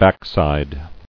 [back·side]